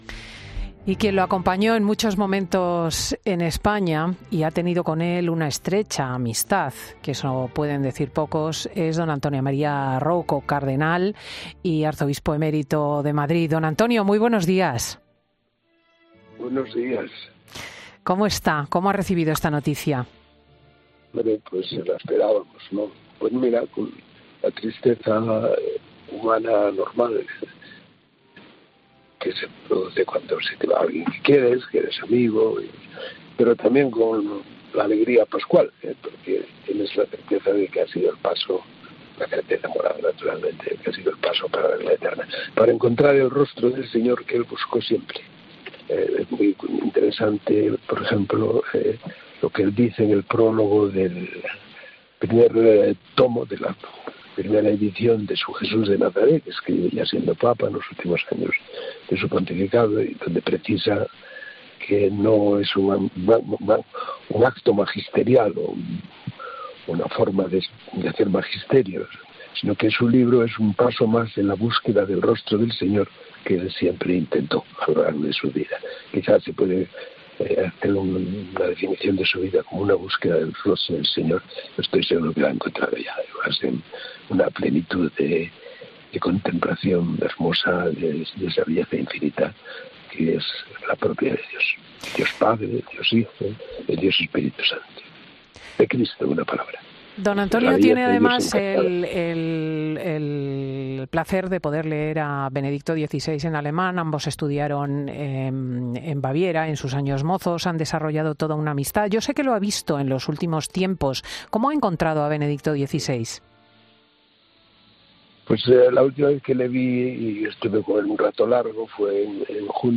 , en una entrevista realizada por